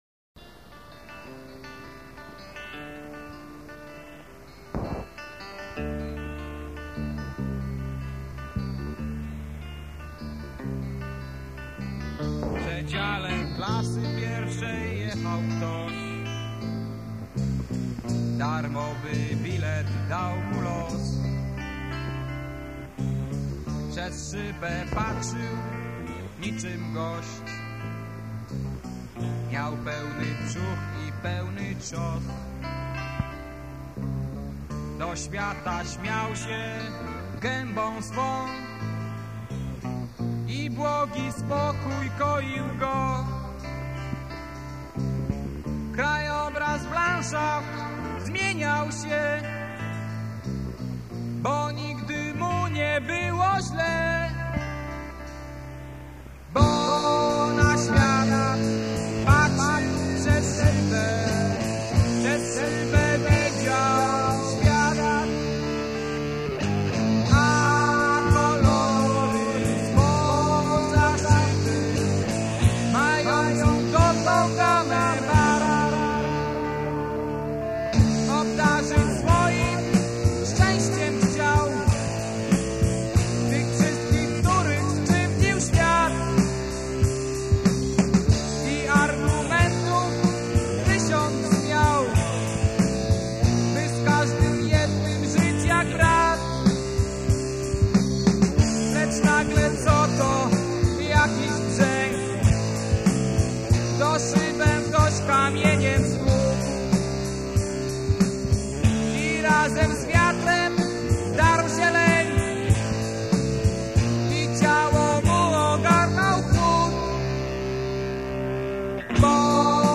perkusja
gitary wokal